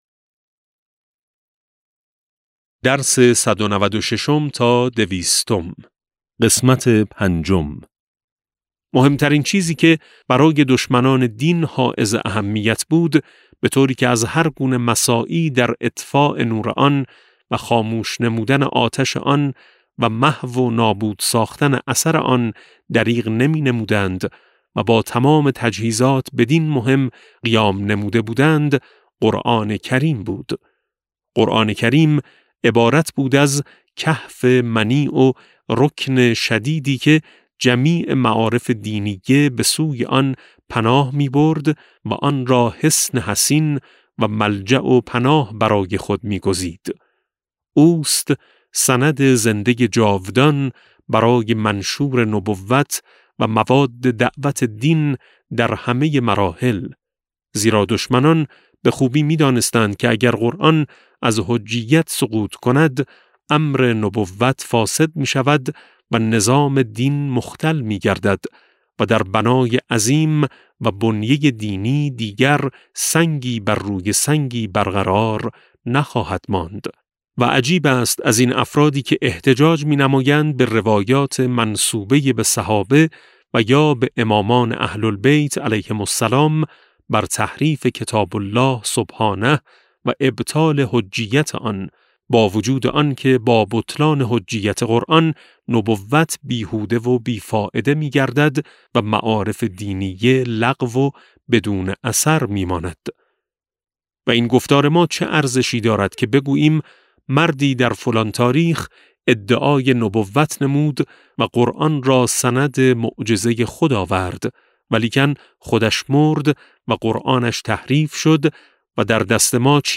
کتاب صوتی امام شناسی ج14 - جلسه5